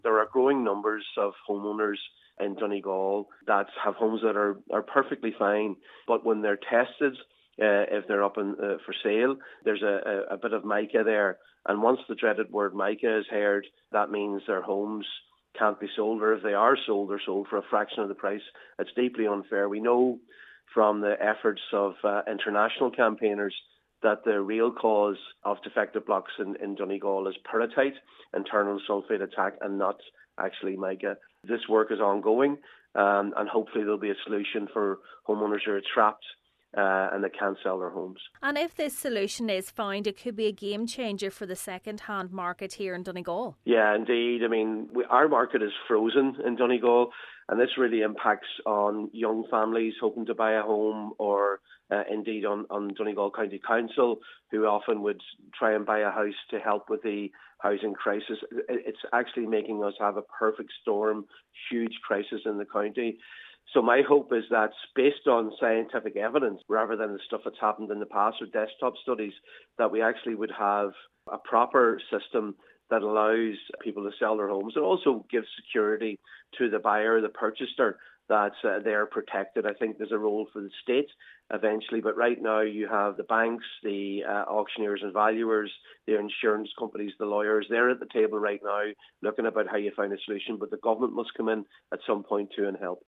Donegal Deputy Padraig MacLochlainn believes the Government has a role to play in discussions: